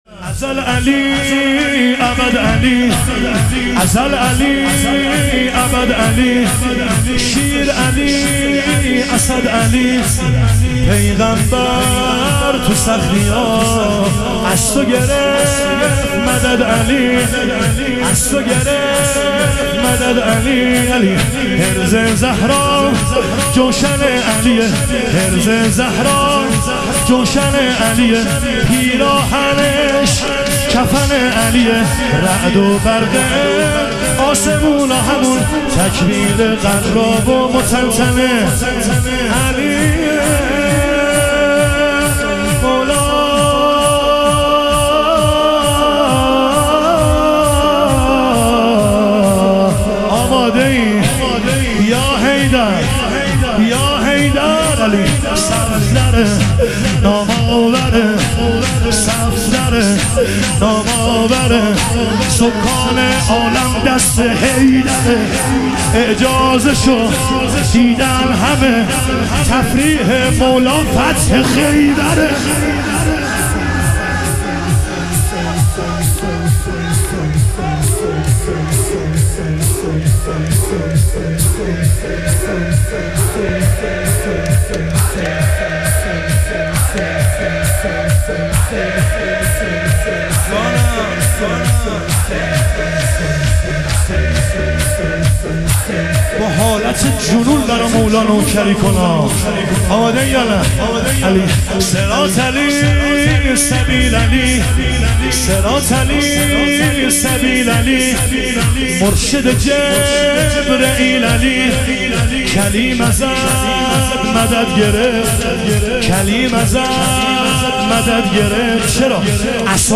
شهادت حضرت خدیجه علیها سلام - شور